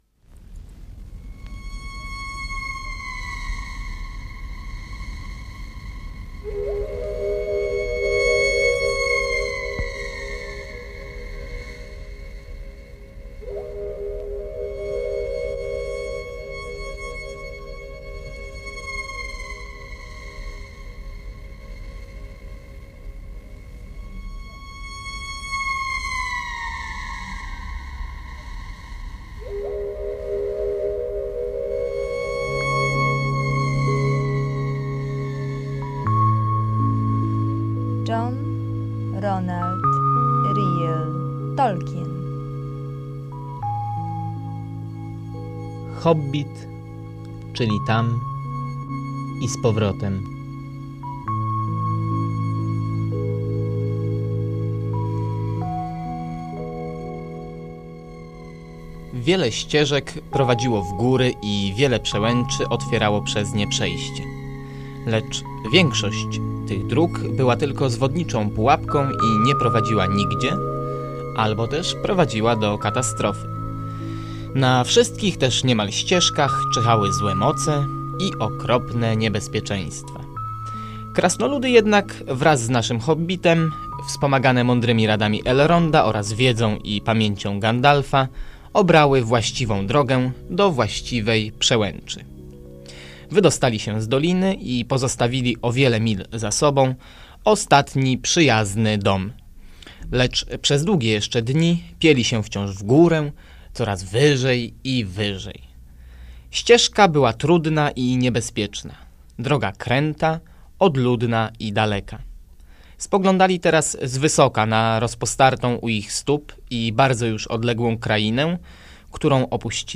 Słuchowisko na podstawie opowiadania J.R.R.Tolkiena